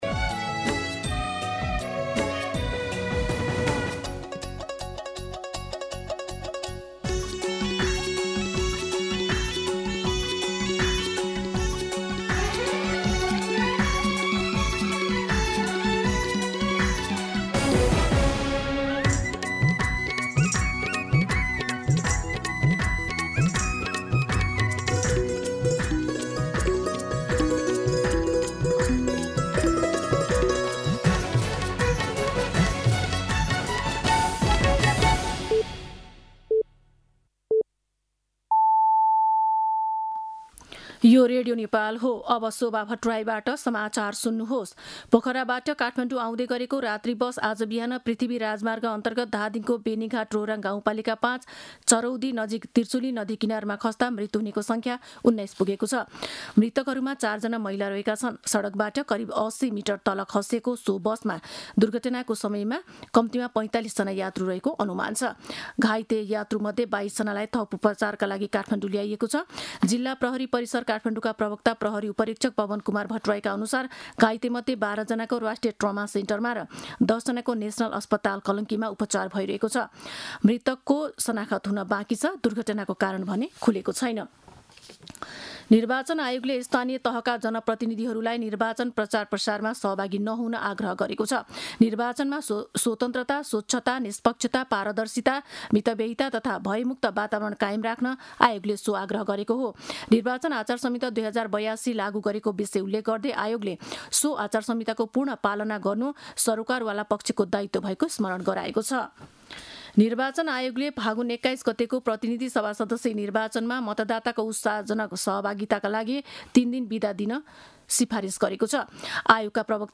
An online outlet of Nepal's national radio broadcaster
मध्यान्ह १२ बजेको नेपाली समाचार : ११ फागुन , २०८२